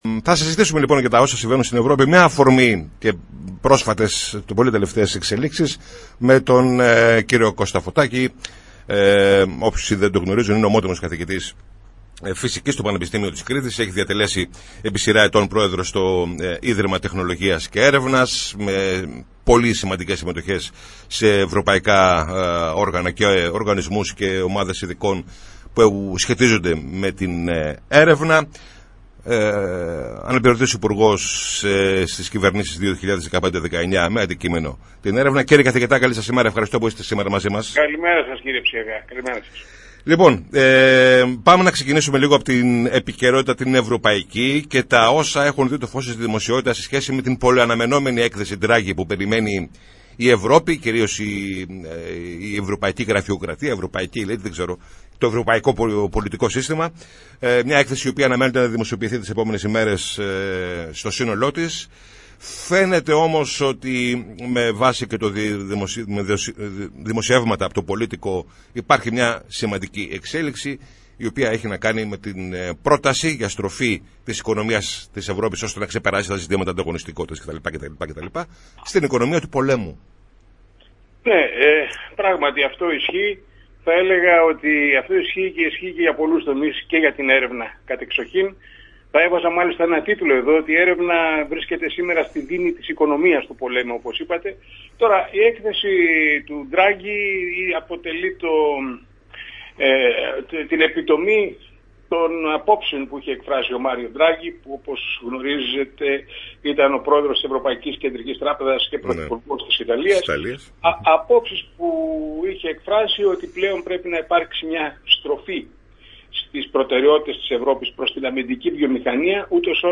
Καλεσμένος ο Κώστας Φωτάκης | 06.09.2024
μίλησε στην εκπομπή «Πολιτική Ημερολόγιο» της ΕΡΤ Λάρισας